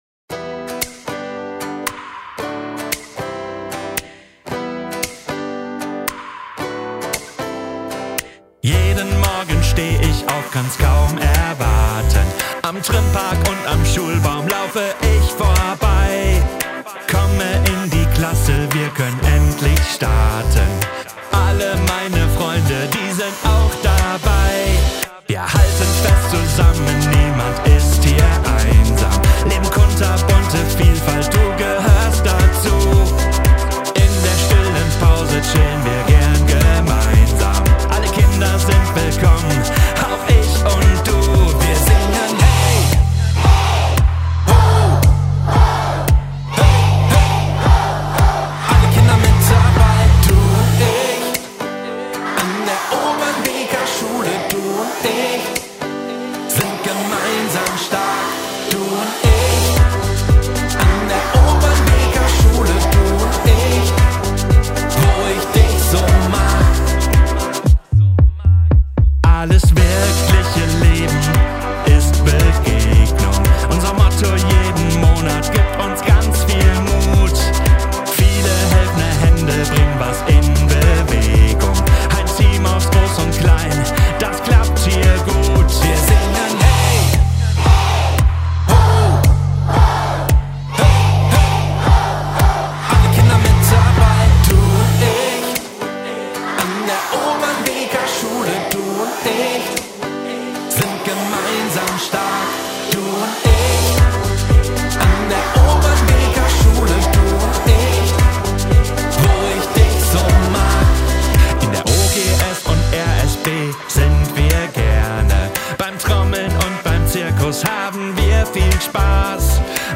Das ist unser Schulsong!
SONG